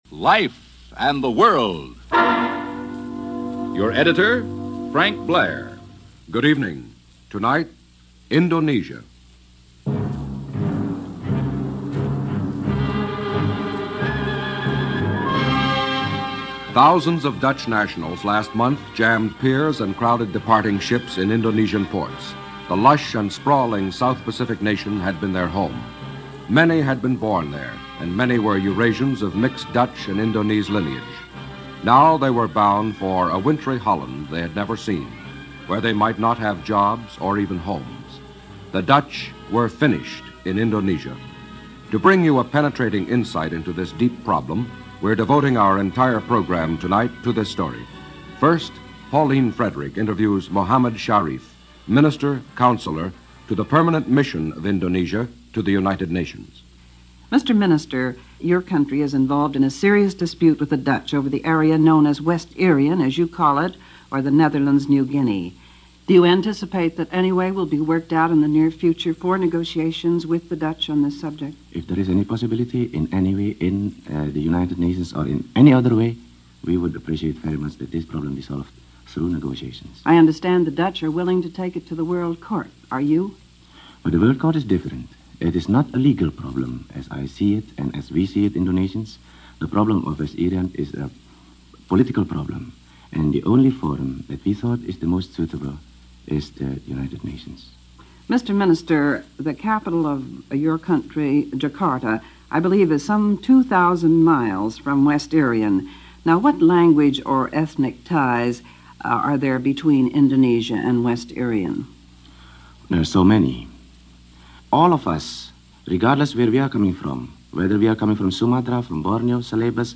The Dutch Quit Indonesia - 1957 - Past Daily Reference Room - Recorded January 7, 1957 - NBC Radio - Life And The World.